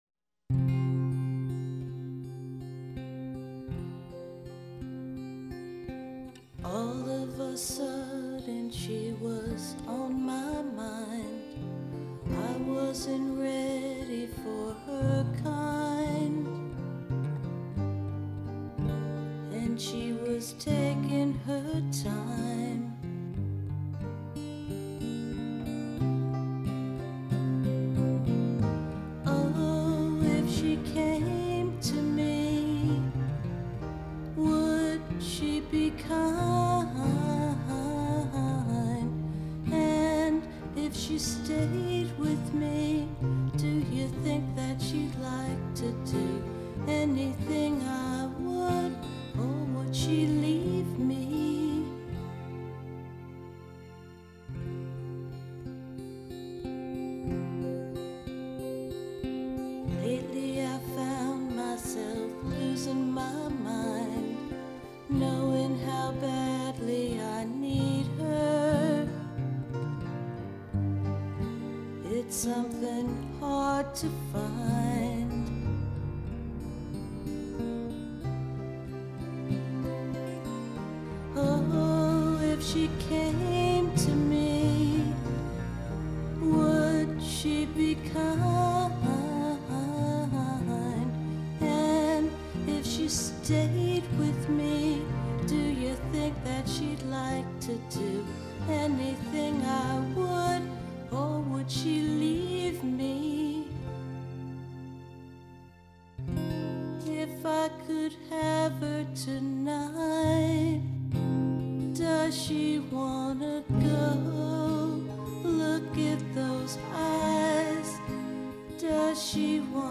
Vocals
Martin acoustic, Piano, Synthesizer